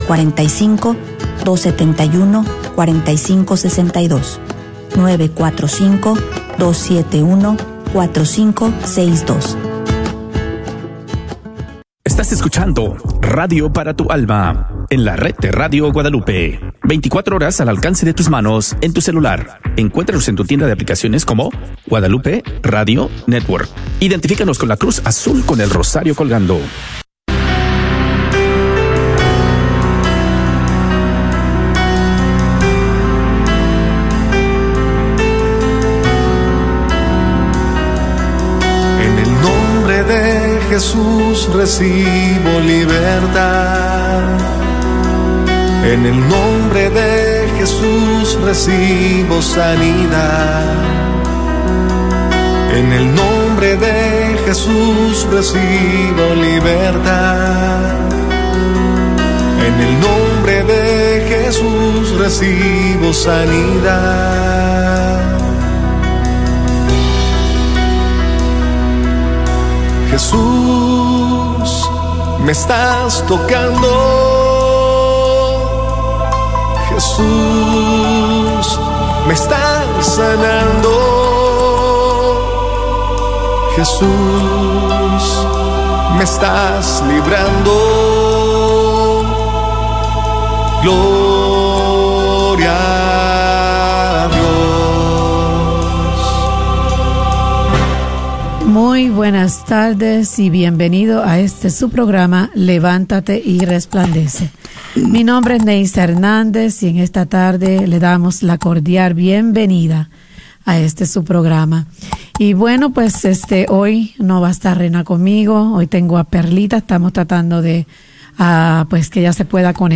Levántate y Resplandece Levántate y Resplandece - Jueves 30 de octubre Este programa fue emitido por primera vez el Jueves 30 de octubre Share this episode on: Levántate y Resplandece una hora de Peticiones de Oracion con El Equipo de Levantate y Resplandece; Escucharas temas para renovar tu espíritu y podrás acompañar a otros en oración por uno de sus integrantes.